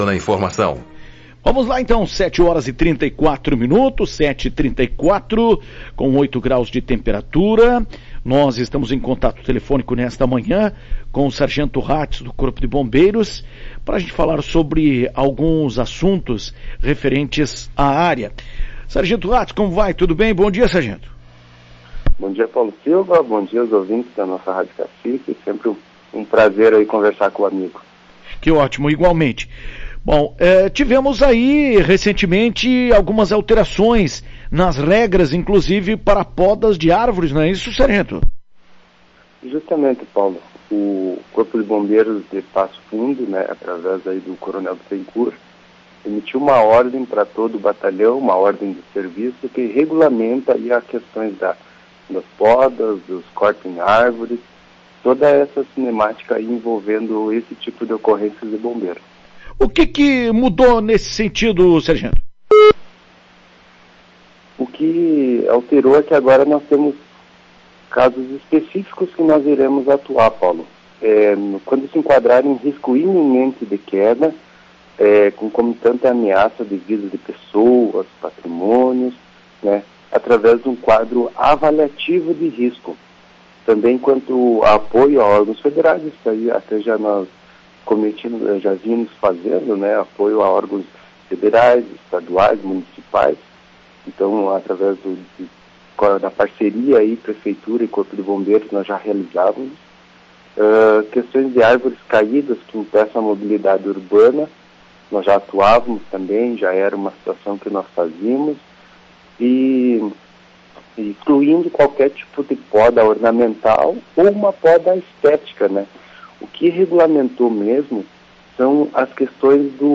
Em entrevista à Tua Rádio Cacique na manhã desta quinta-feira